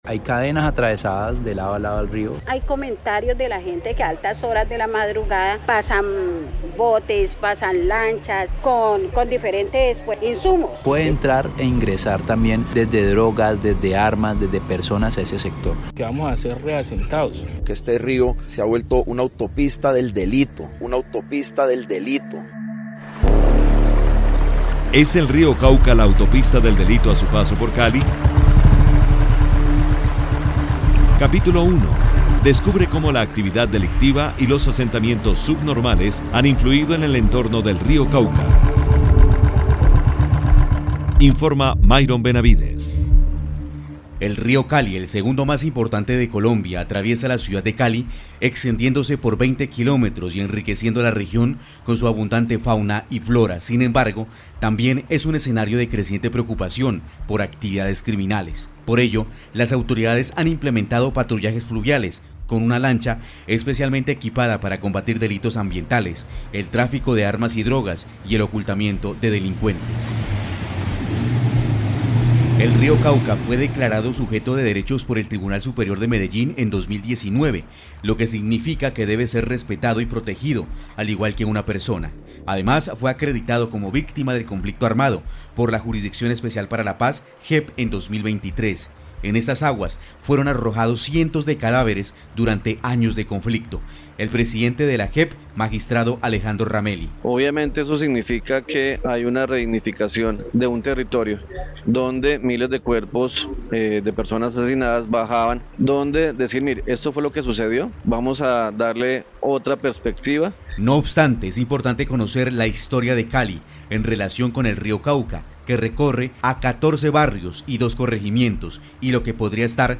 Pasado y presente del río Cauca, con testimonios de la realidad del más importante cauce que cruza a Cali.